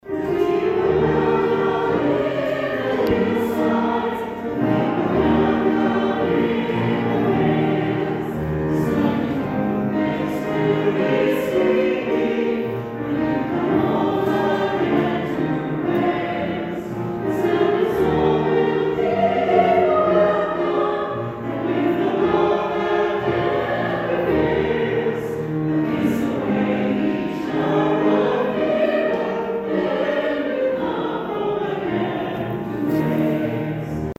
138th St. David’s Day celebration and concert held at Emporia Presbyterian Church West Campus Sunday afternoon
a choir made up of community members
The selections for Sunday’s concert included the singing of both the American and Welsh national anthems and various hymns, including the traditional Welsh welcome song “We’ll Keep A Welcome Hill in the Hillside.”
welsh-welcome.mp3